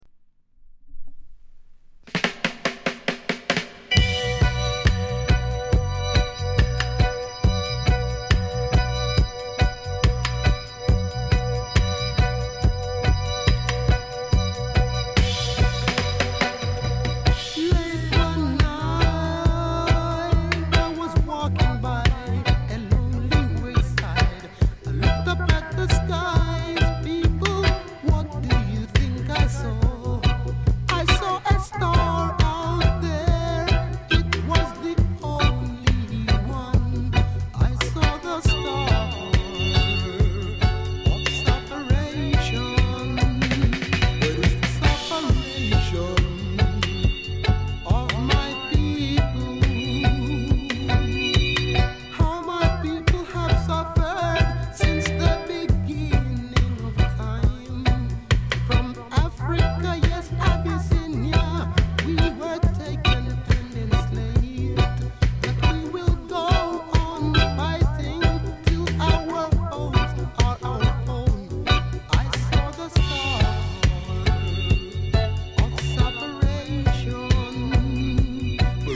ソウルフル *